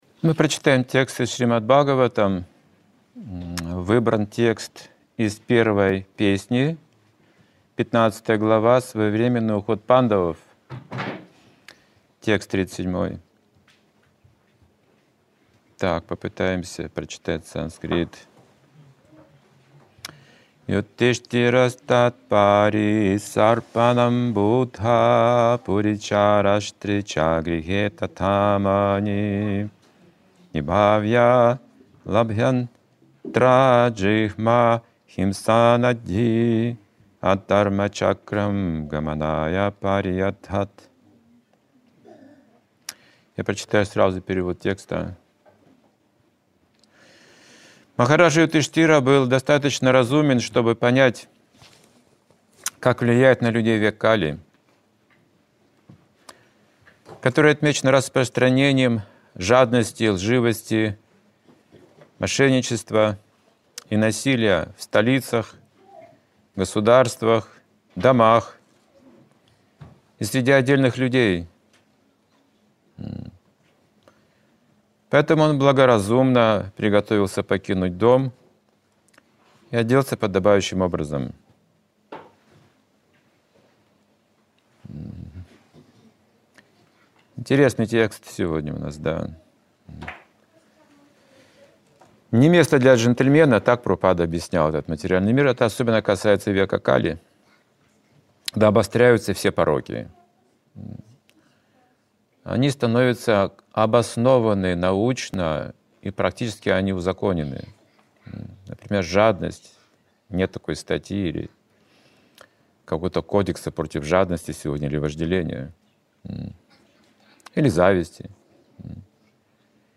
Лекции и книги